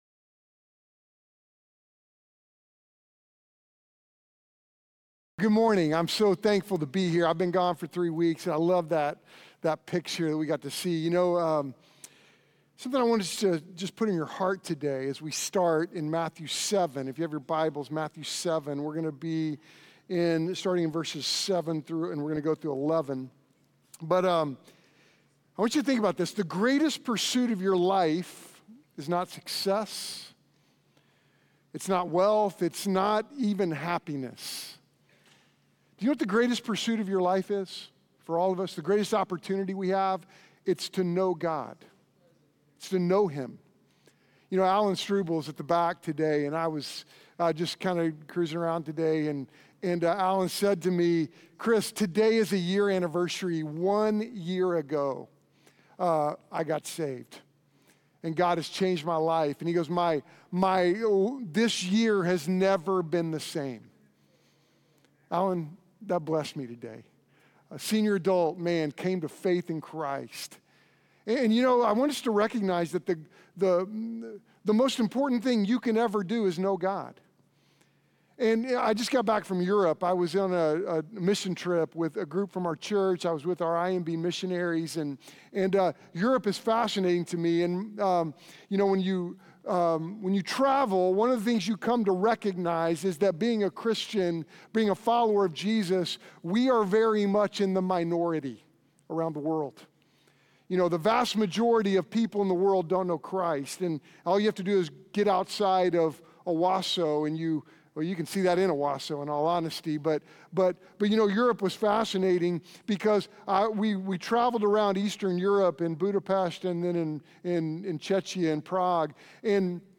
When Christ saves you, His Holy Spirit indwells you providing an interactive relationship extending through heaven. In this incredible sermon, Jesus unveils the benefits of this interactive relationship that is unparalleled in every season of life.